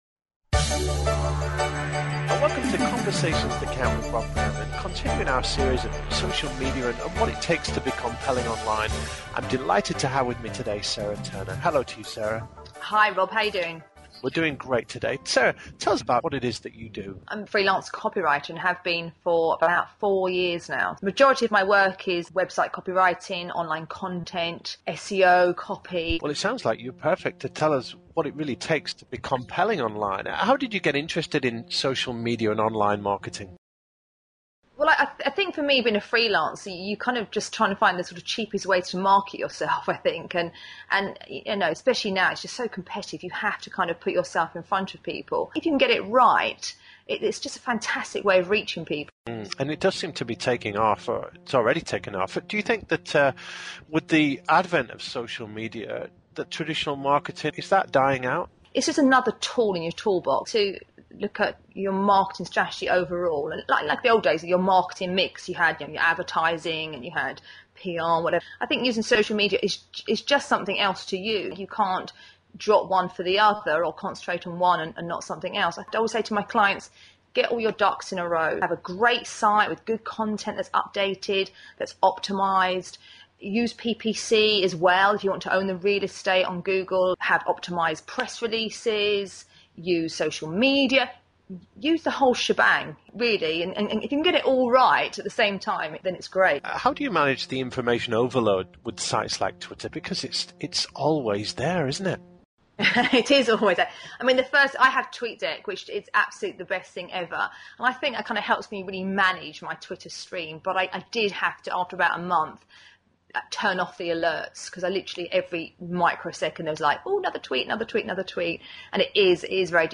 All about Social Media: An interview